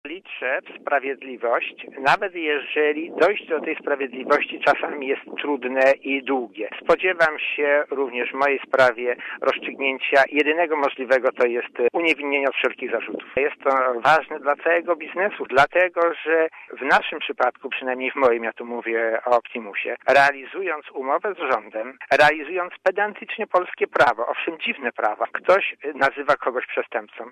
Dla Radia Zet mówi Roman Kluska (213 KB)